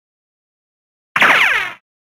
Cartoon Ricochet Sound
cartoon
Cartoon Ricochet